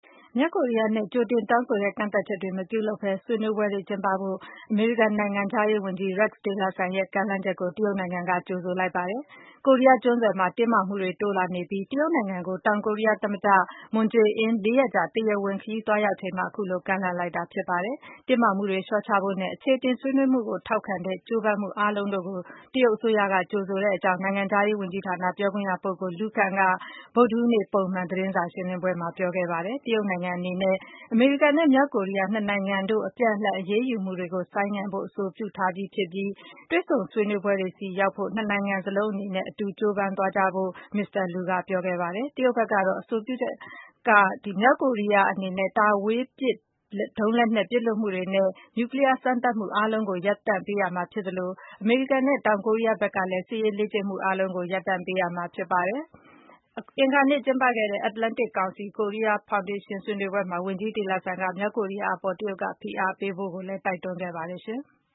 Secretary of State Rex Tillerson speaks at the 2017 Atlantic Council-Korea Foundation Forum in Washington